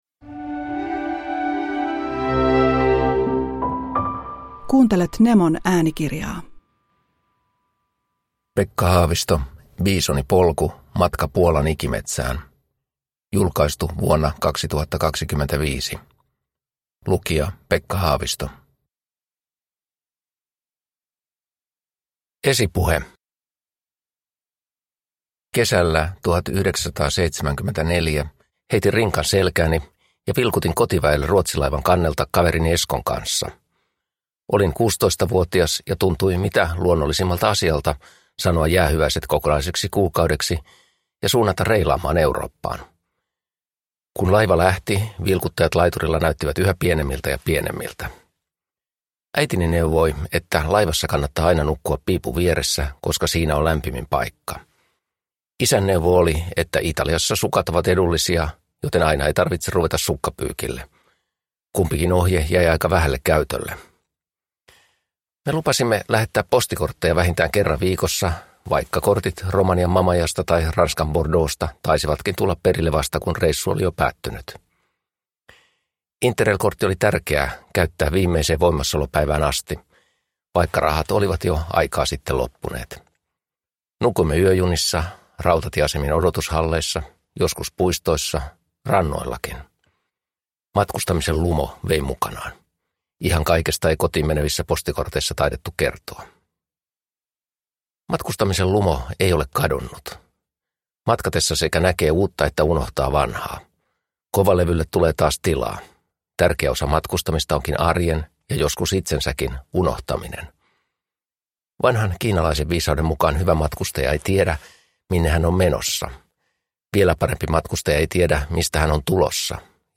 Biisonipolku – Ljudbok
Uppläsare: Pekka Haavisto